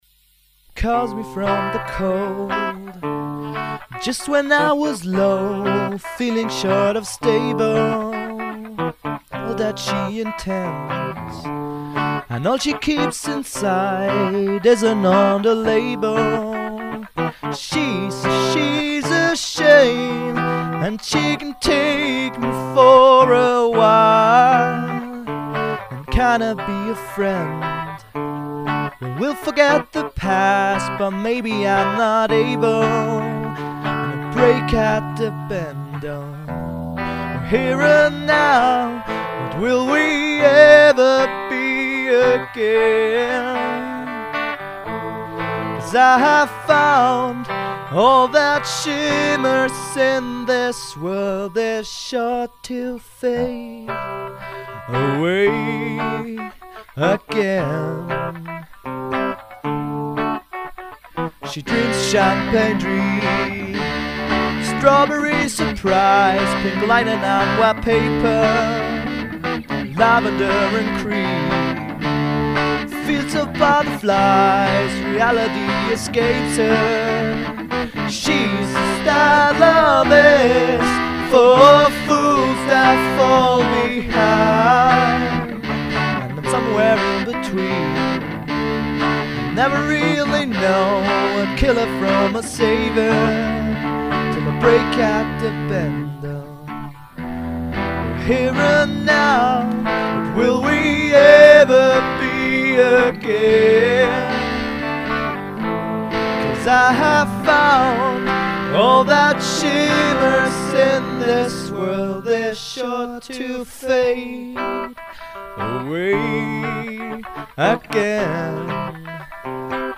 Little less distortin though.